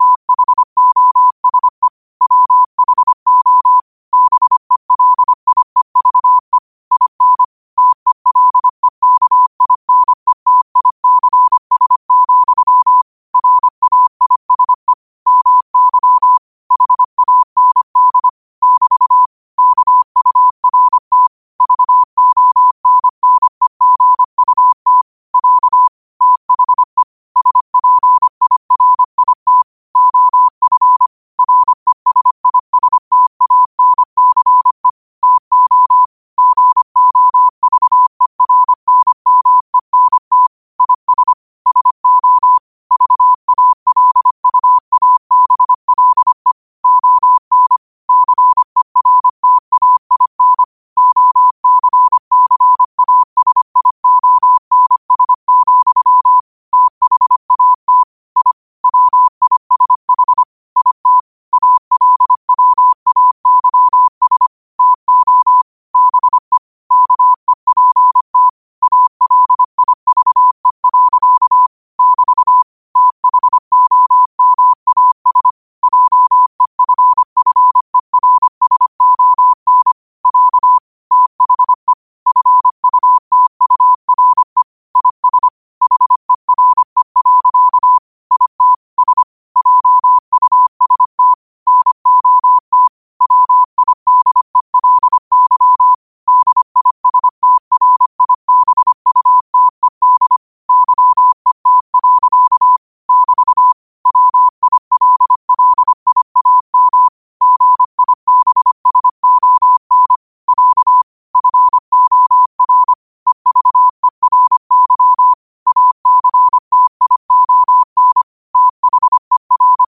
New quotes every day in morse code at 25 Words per minute.